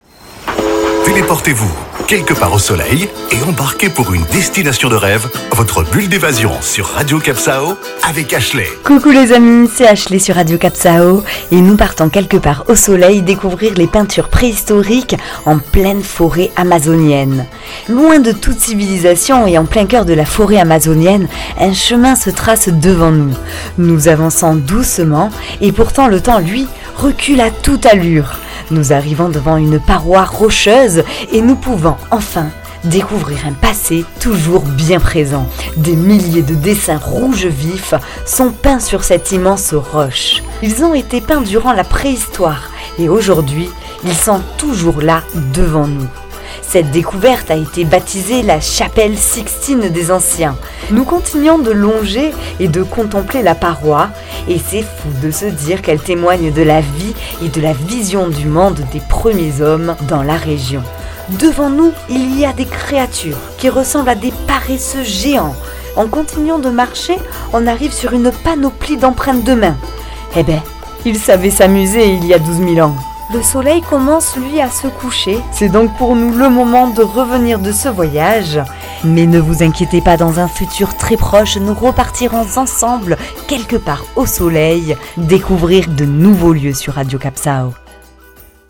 Carte postale sonore : fermez les yeux et voyagez dans le temps, loin de toute civilisation, à la découverte de dessins rouge vif ...